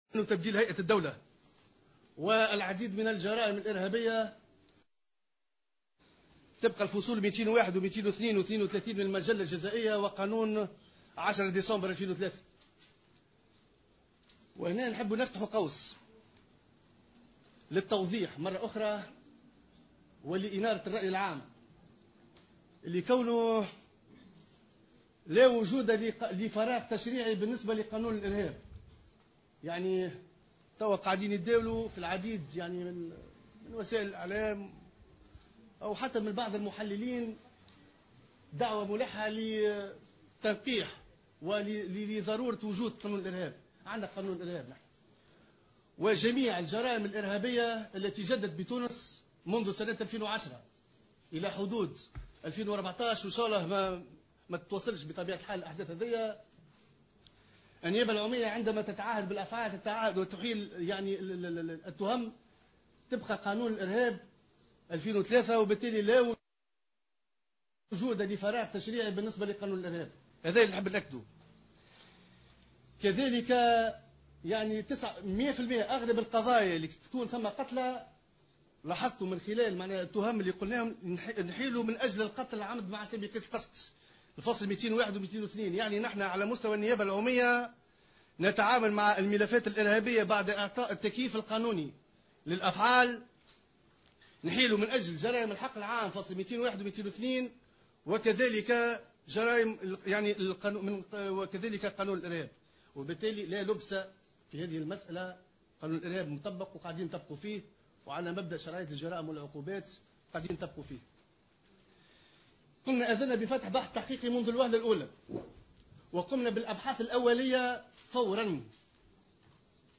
عقد الناطق الرسمي باسم النيابة العمومية سفيان السليطي اليوم الخميس ندوة صحفية لإطلاع الرأي العام على مجريات التحقيق في الهجوم الإرهابي على نزل بسوسة الجمعة الماضي.